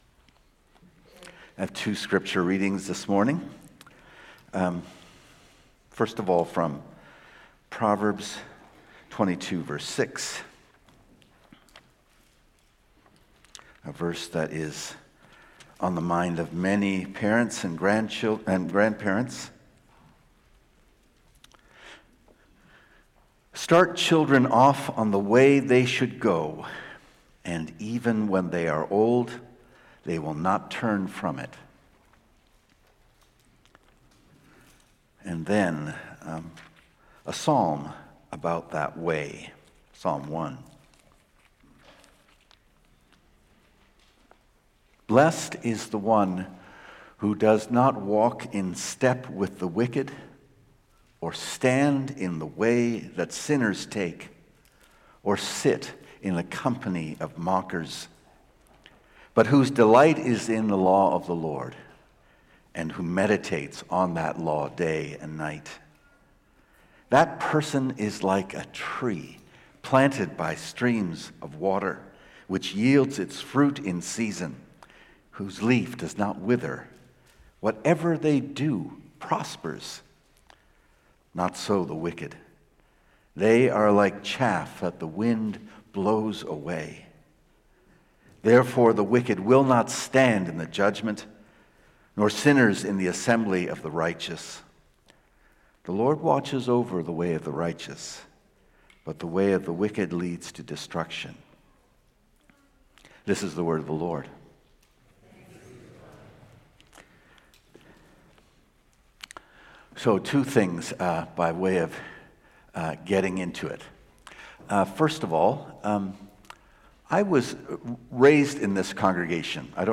Sermons | Community Christian Reformed Church